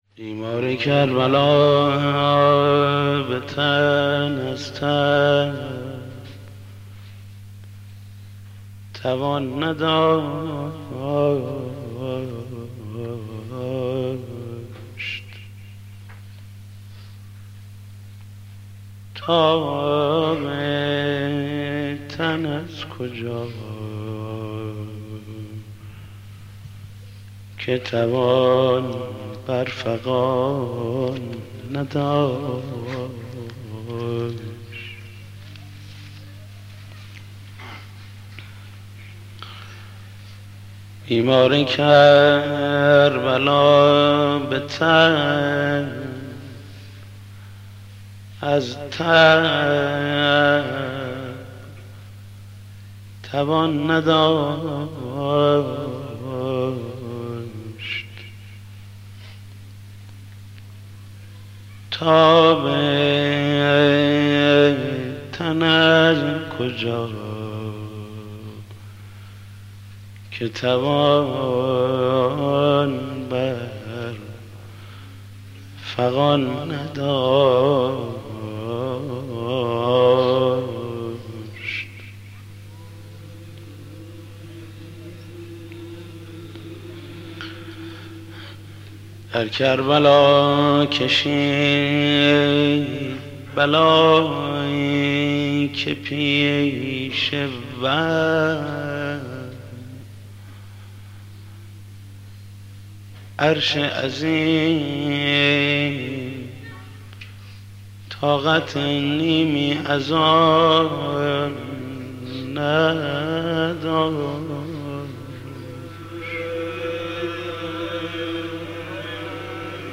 محمود کریمی مداح
مناسبت : دهه دوم محرم